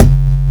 Kik Hum.wav